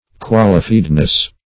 Qualifiedness \Qual"i*fied`ness\